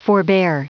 Prononciation du mot forbear en anglais (fichier audio)
Prononciation du mot : forbear